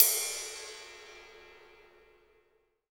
D2 RIDE-09.wav